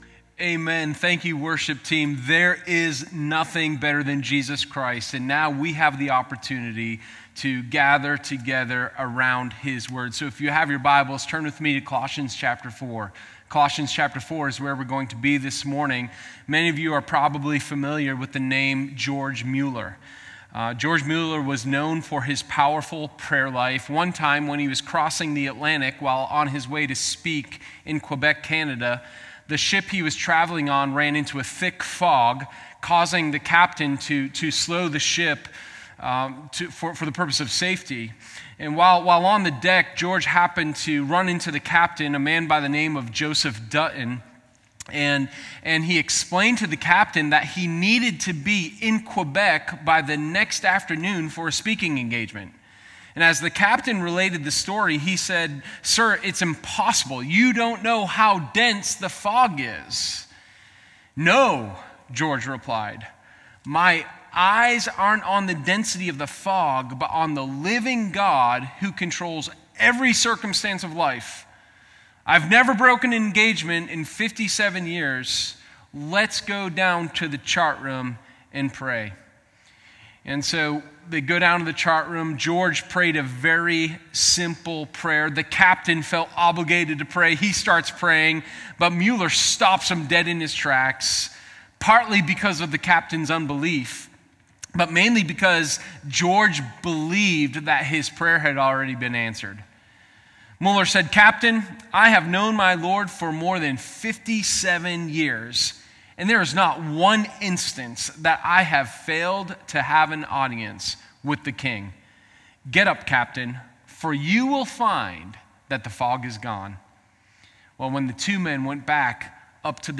Sermon0614_Unused-Weapon.m4a